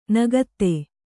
♪ nagatte